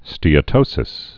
(stēə-tōsĭs)